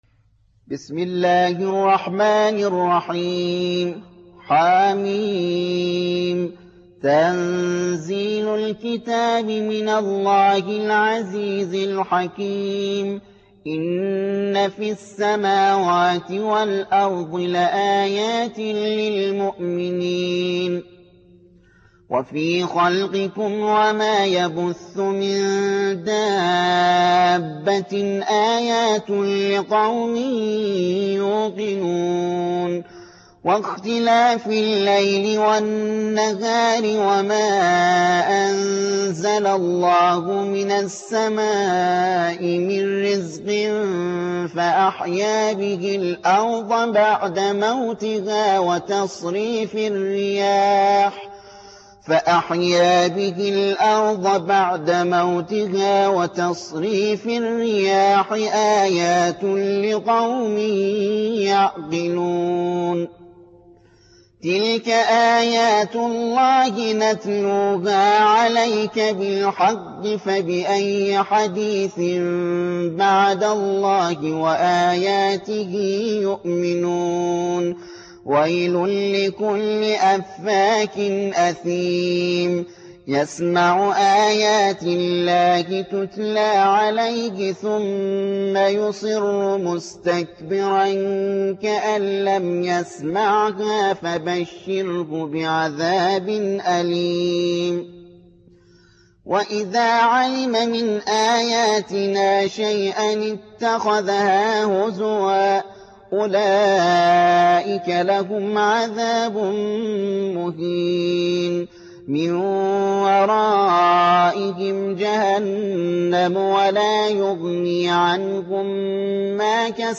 45. سورة الجاثية / القارئ